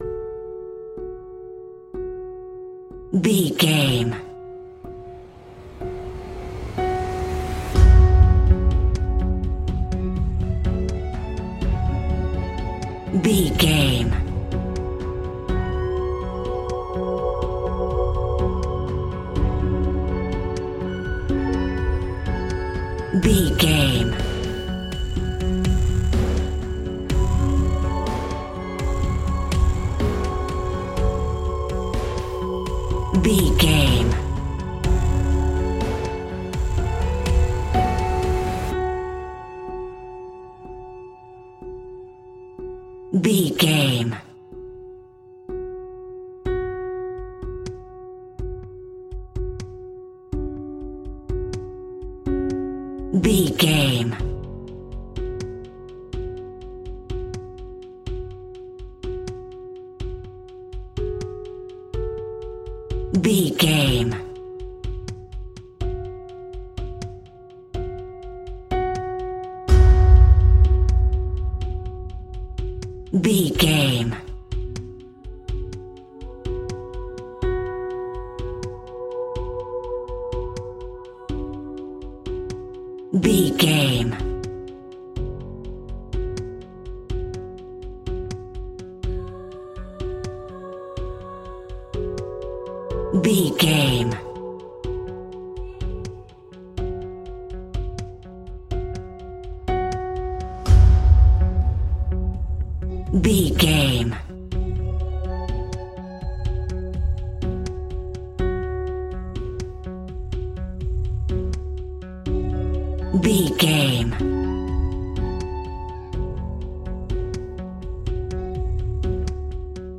Ionian/Major
F♯
electronic
techno
trance
synths
synthwave
drone
instrumentals